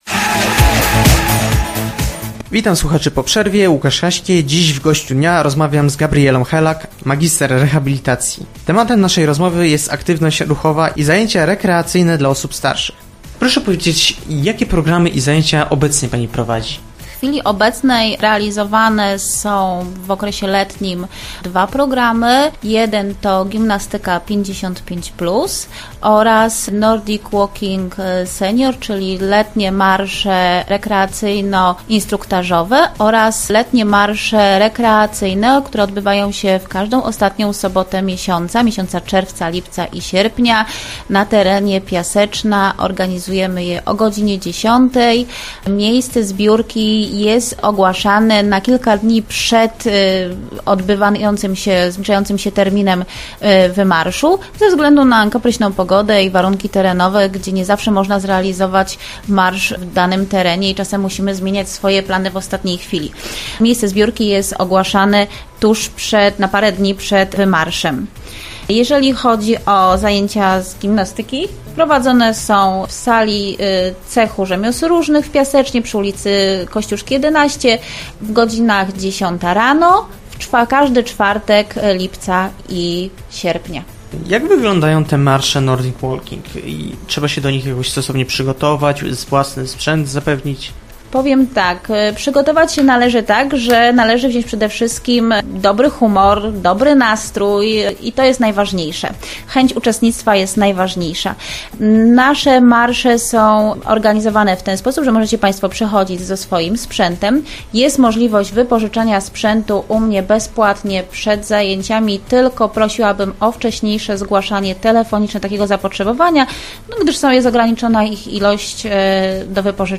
gość-dnia-cz-2.mp3